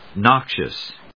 音節nox・ious 発音記号・読み方
/nάkʃəs(米国英語), nˈɔkʃəs(英国英語)/